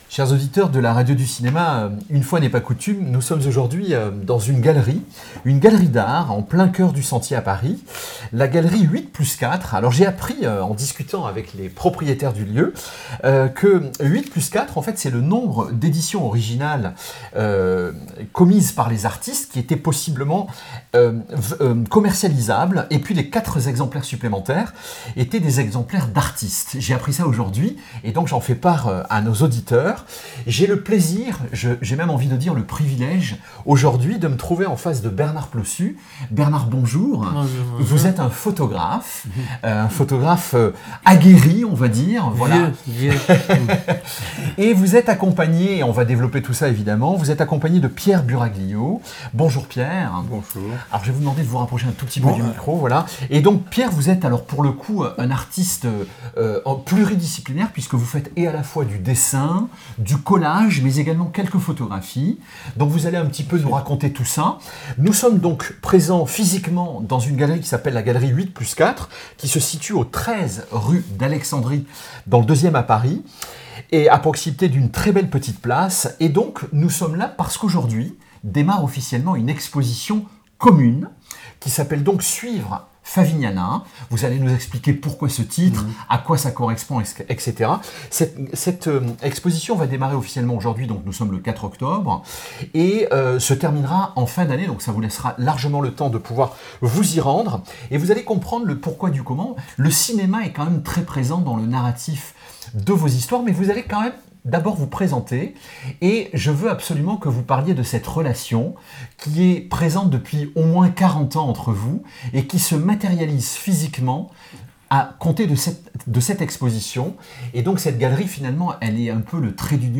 Les podcasts, interviews, critiques, chroniques de la RADIO DU CINEMA